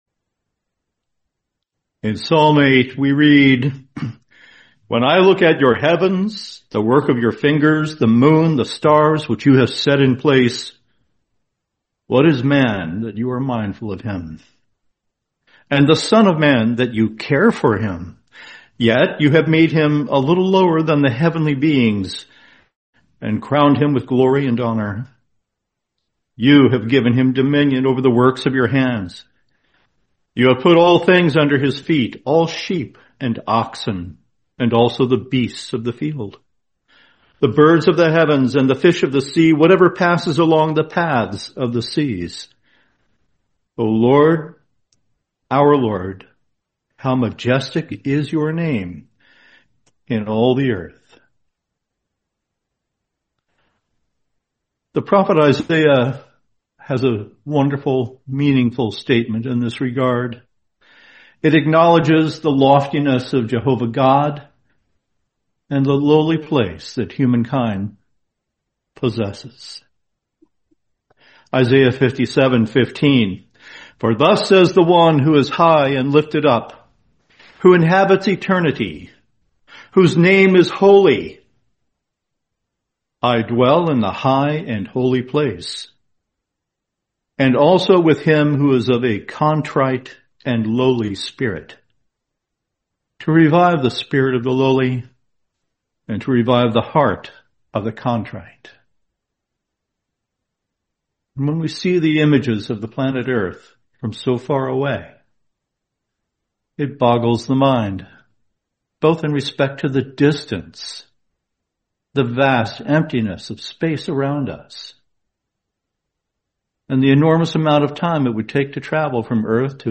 Series: 2025 Toronto Convention